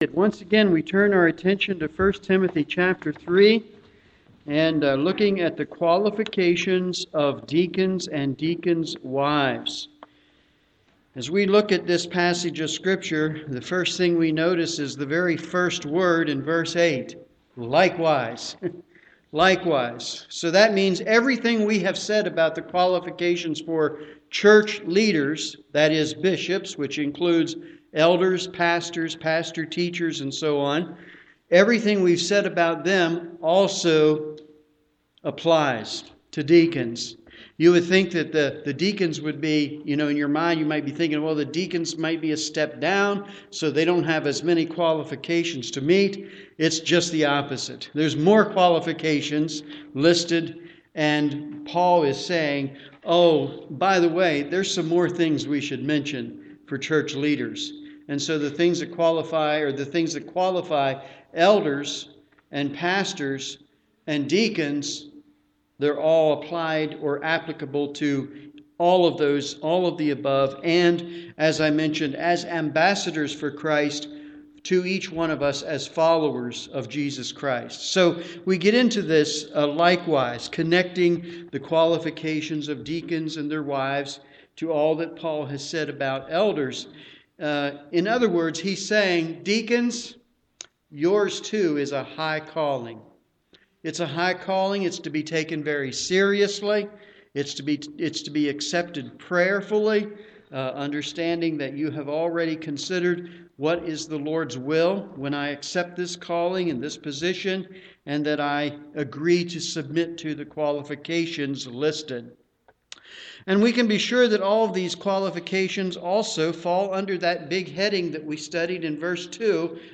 I Tim. 3:8-13 - Qualifications of Deacons - Waynesboro Bible Church